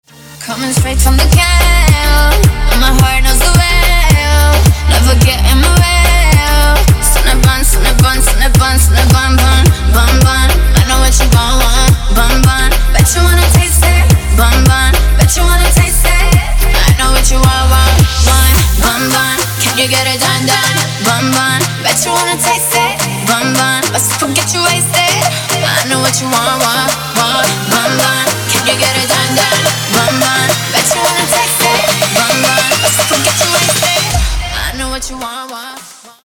• Качество: 224, Stereo
женский вокал
dance
club
Moombahton
vocal